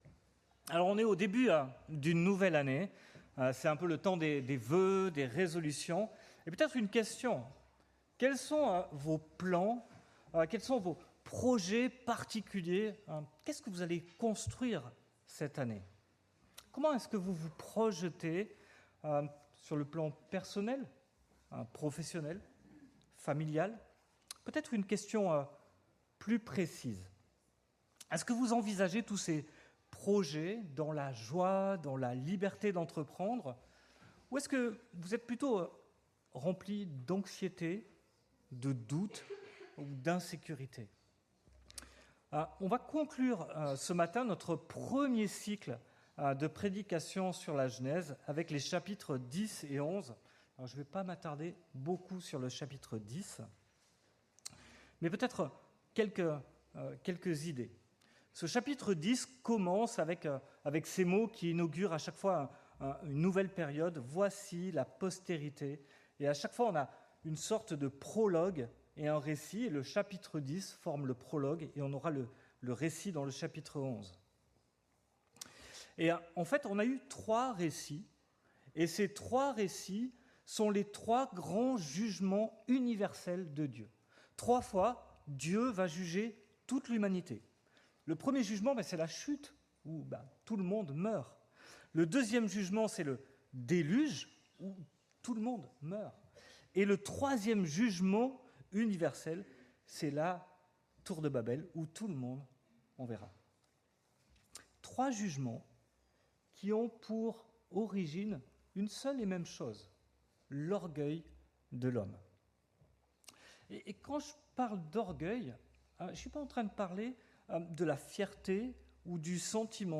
Prédications textuelles